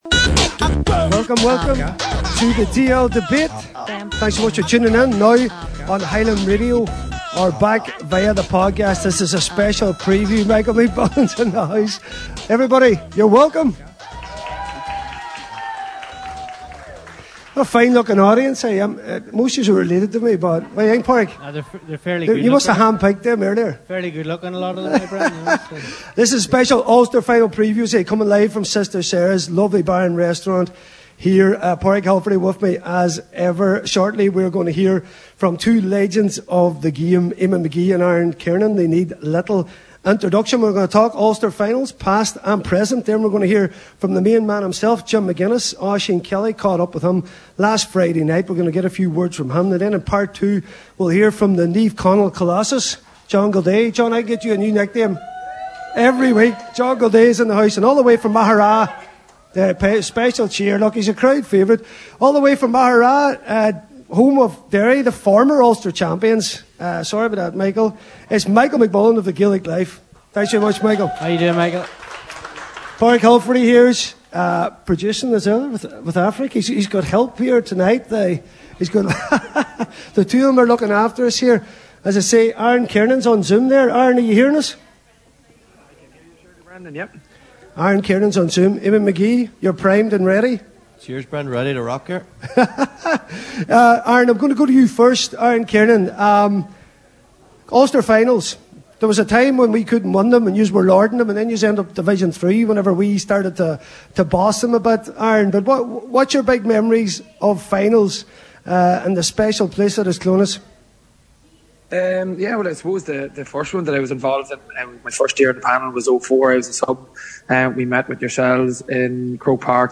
an Ulster Final Special LIVE from Sister Sara’s in Letterkenny